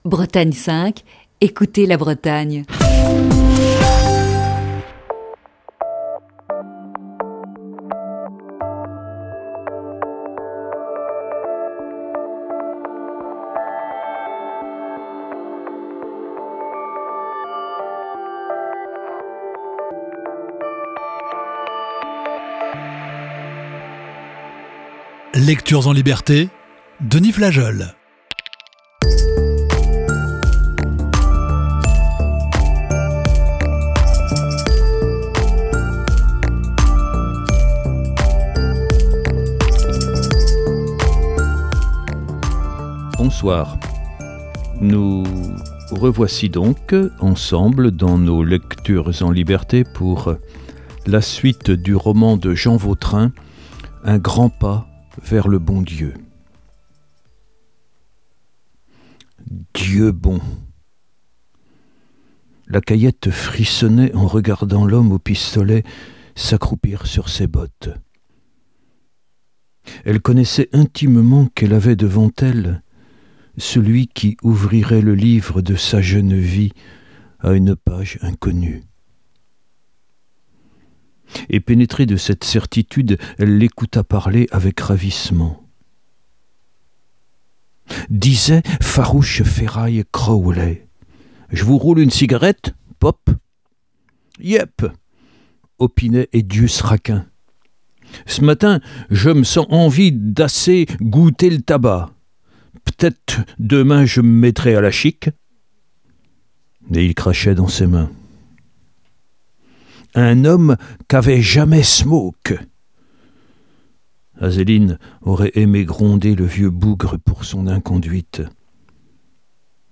Émission du 6 avril 2021.